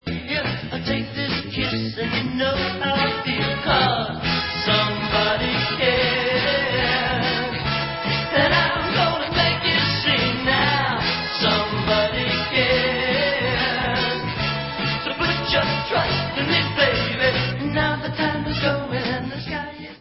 sledovat novinky v oddělení Pop/Oldies